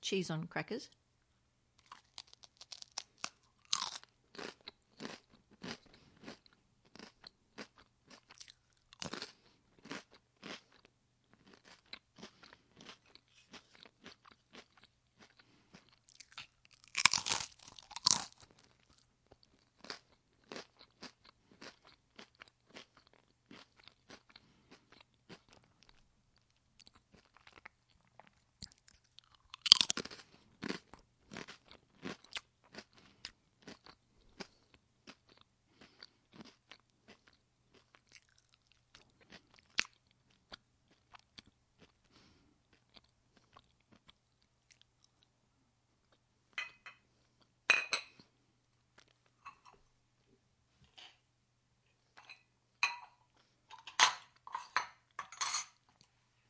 咀嚼饼干
描述：咀嚼和咀嚼饼干，先闭嘴，然后张开嘴。
标签： 咀嚼 饼干 张嘴 味道鲜美 咀嚼 蒙克 脆脆的 饼干 紧缩 饼干 点心 饼干 咀嚼
声道立体声